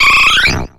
Cri d'Évoli dans Pokémon X et Y.